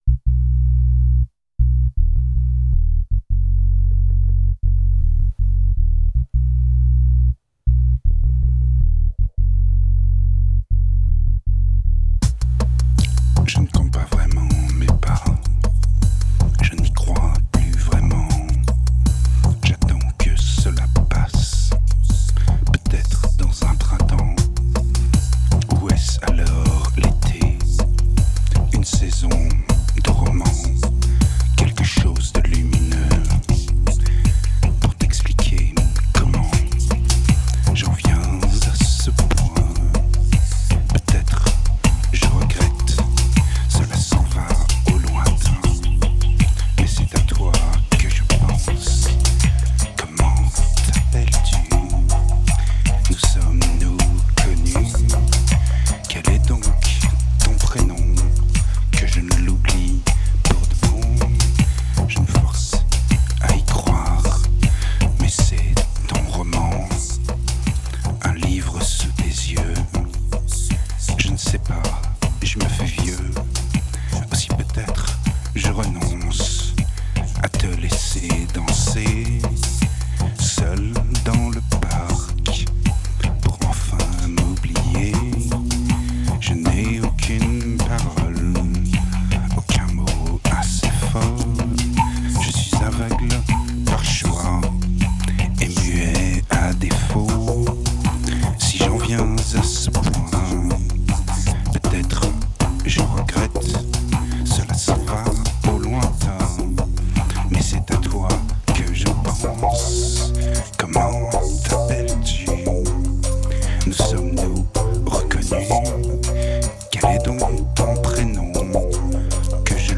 2033📈 - -39%🤔 - 79BPM🔊 - 2009-05-28📅 - -308🌟